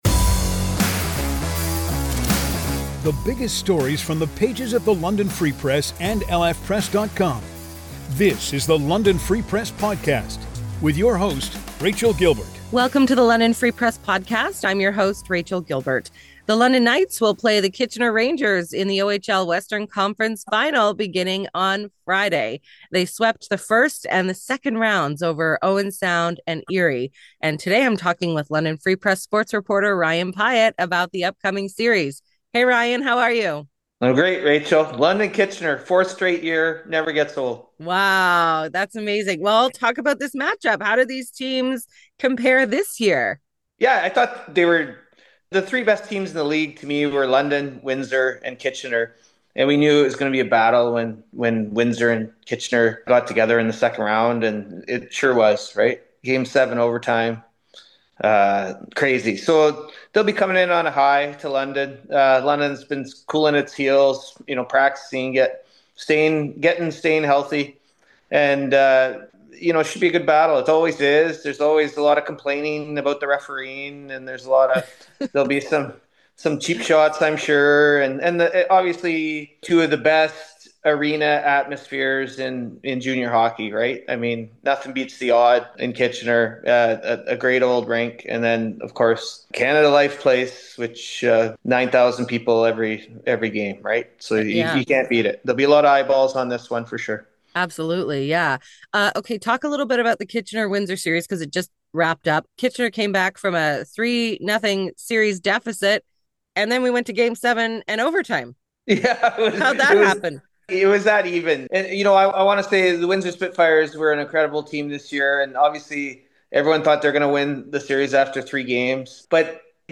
1 WATCH: Is Trump Breaking the Law? A Conversation with Jed Rubenfeld, Lawrence Lessig, and Jonathan Adler.